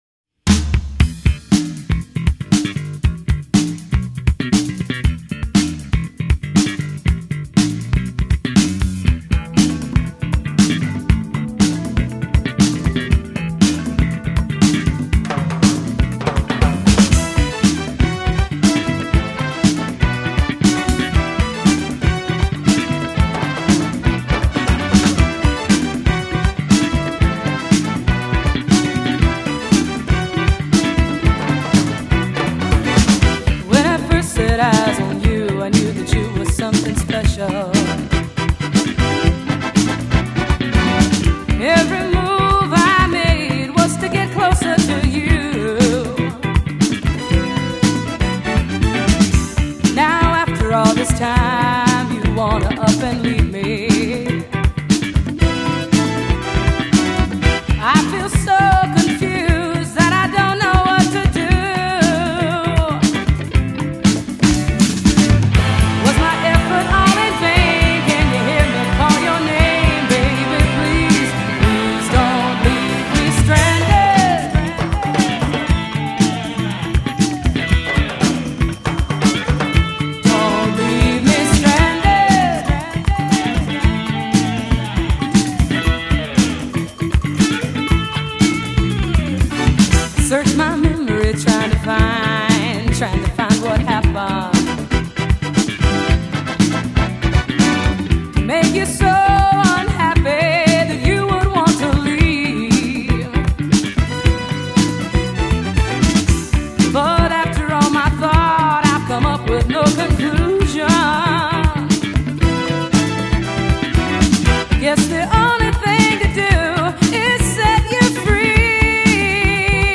vocals, guitar
Drums
Sax
Percussion
Guitar, keyboards